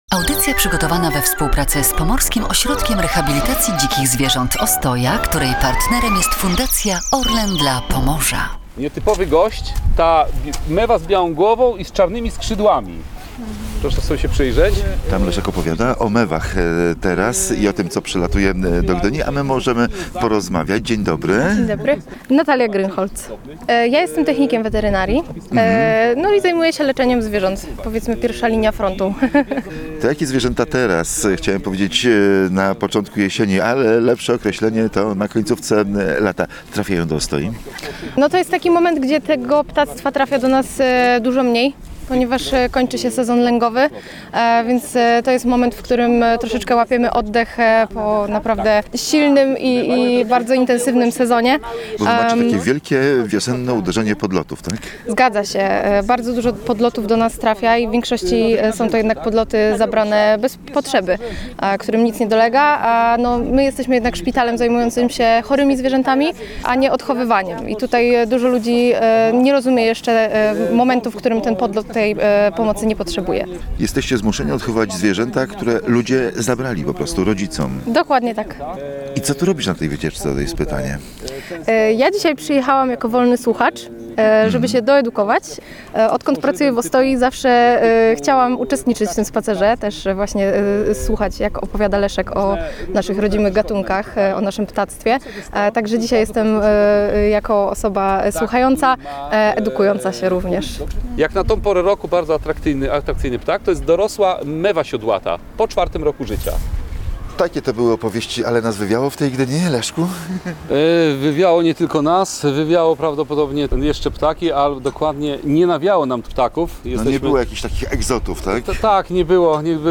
Audycja jest relacją z kolejnego ptasiego spaceru z Pomorskim Ośrodkiem Rehabilitacji Dzikich Ptaków Ostoja. Wycieczka miała miejsce na Skwerze Kościuszki w Gdyni i zaczęła się od opowieści o dokarmianiu ptaków.